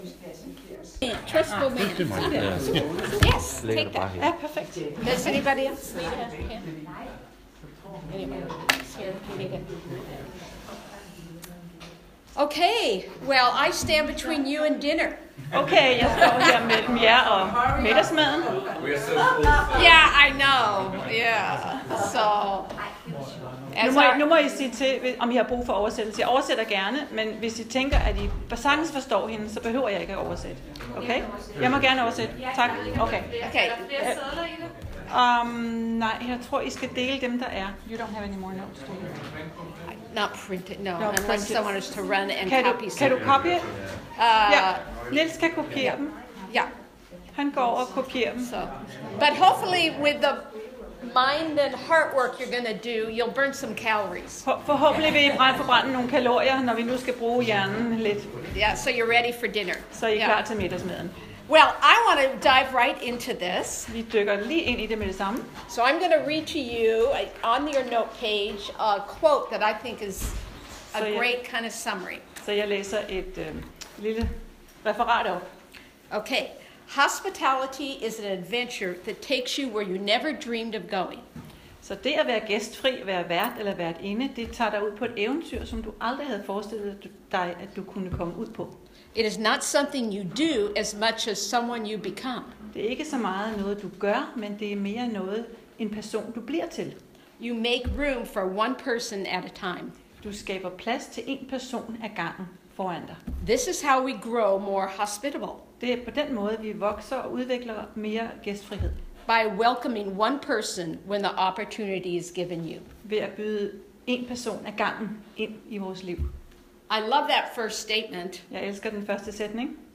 20. juli 2017 Type af tale Seminarer etc. MP3 Hent til egen PC